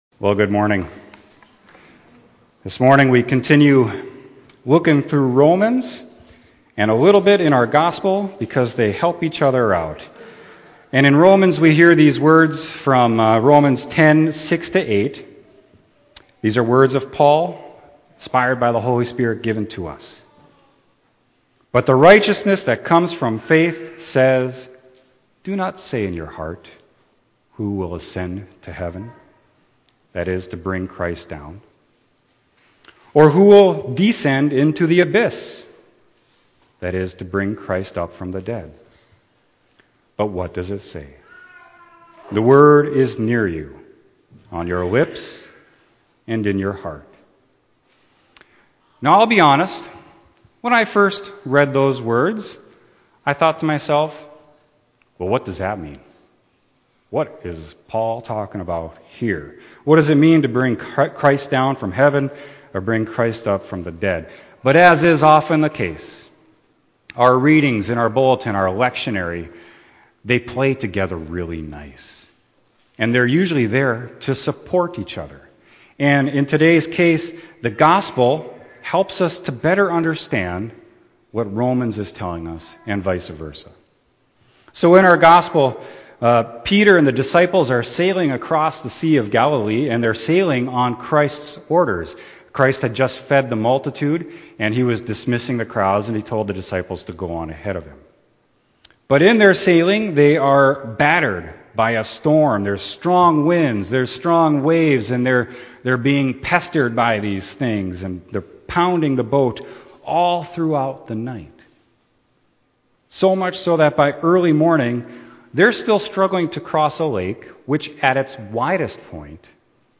Sermon: Romans10/Matt 14